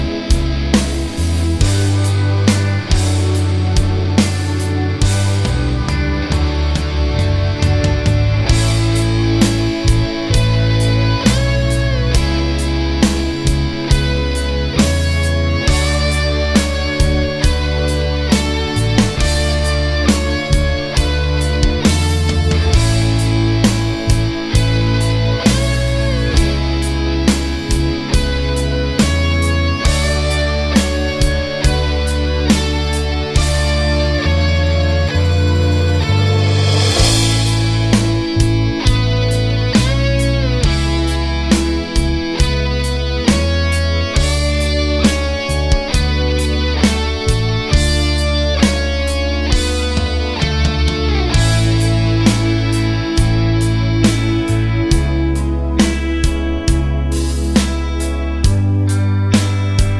no Backing Vocals Rock 4:45 Buy £1.50